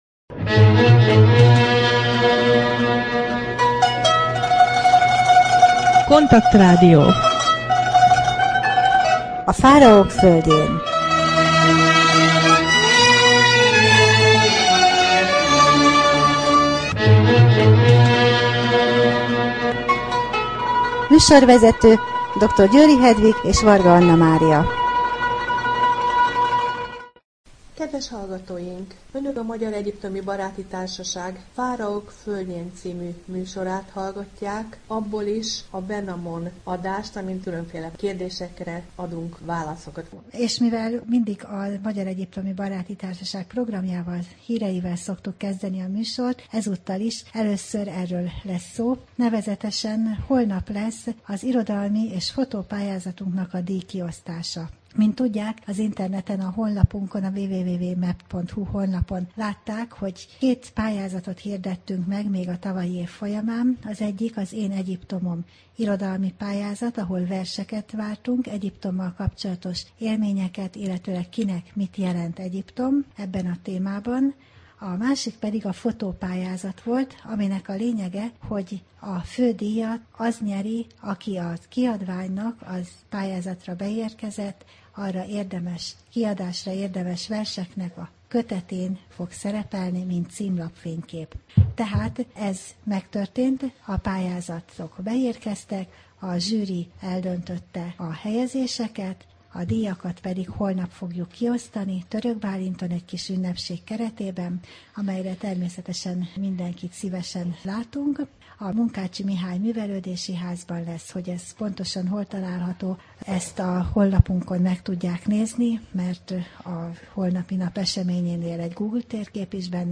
Wenamon beszélgető rovat: Mükerinosz, a zene, a kutya és a Sors | Magyar Egyiptomi Baráti Társaság